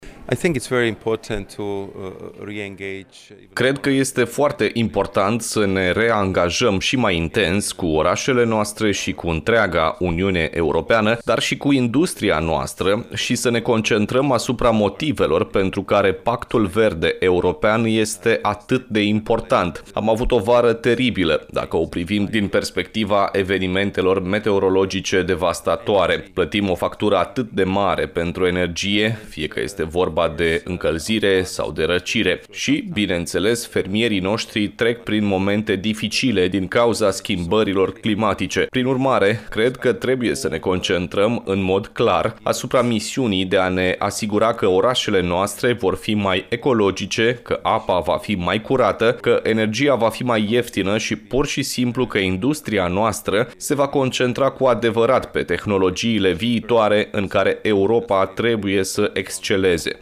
13-oct-Maros-Sefcovic-tradus-2.mp3